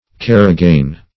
Search Result for " karagane" : The Collaborative International Dictionary of English v.0.48: Karagane \Kar"a*gane\, n. [Russ. karagan'] (Zool.) A species of gray fox found in Russia.